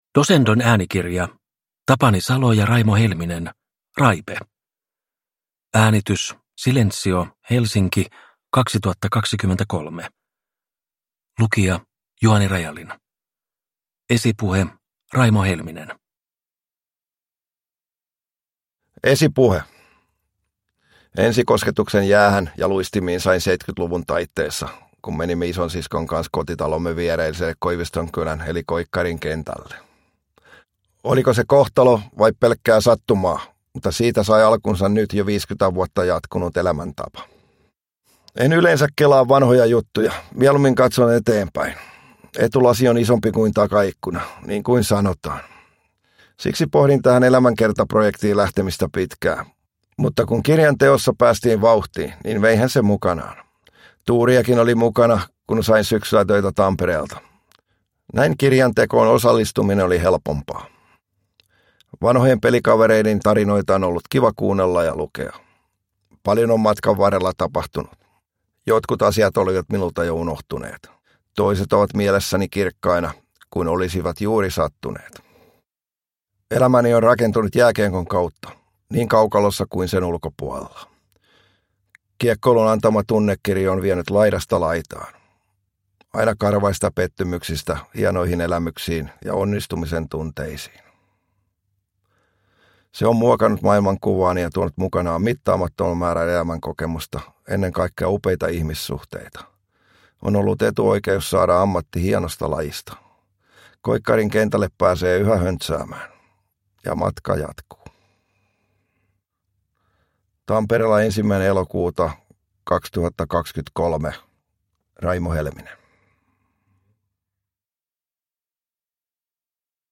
Raipe – Ljudbok – Laddas ner